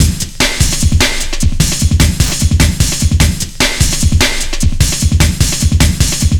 Index of /90_sSampleCDs/Zero-G - Total Drum Bass/Drumloops - 1/track 01 (150bpm)